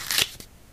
cardOpenPackage2.ogg